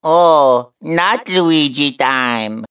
One of Luigi's voice clips in Mario Kart 7